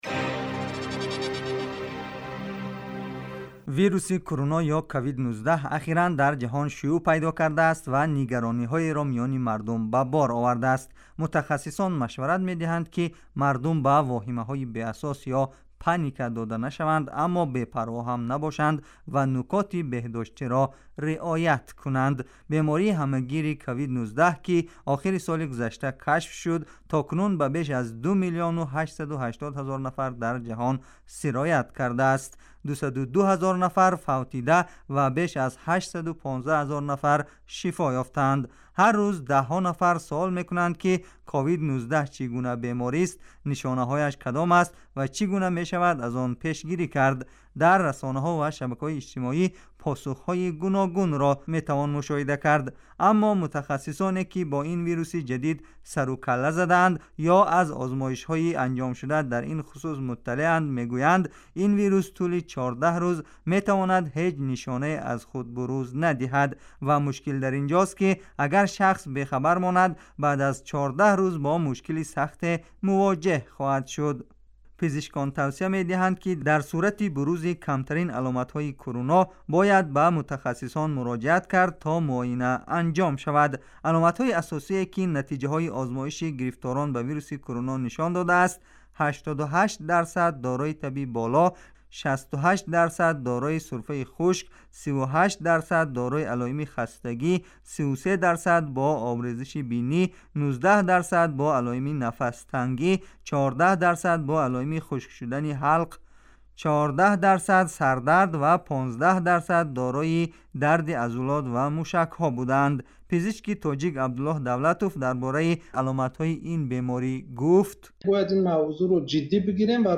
Гузориши вижа